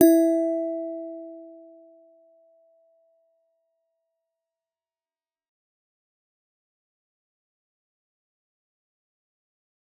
G_Musicbox-E4-f.wav